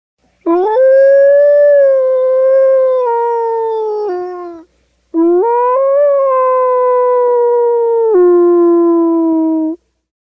Приклад звуку "Вовк"